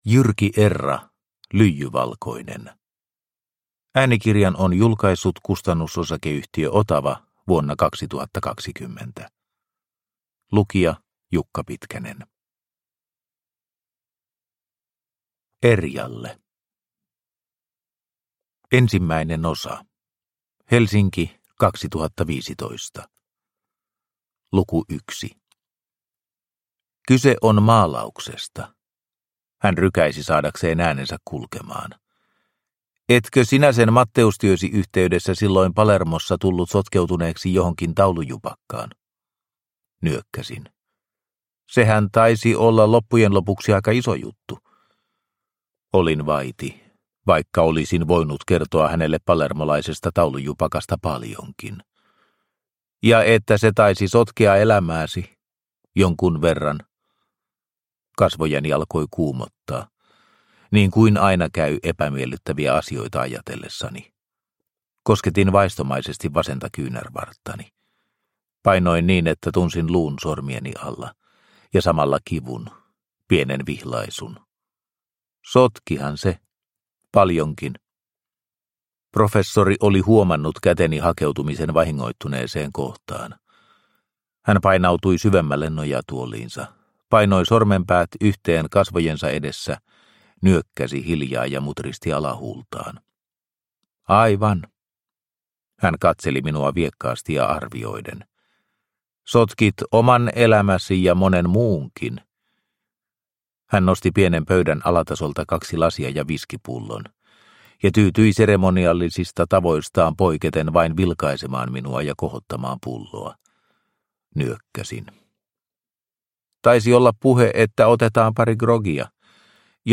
Lyijyvalkoinen – Ljudbok – Laddas ner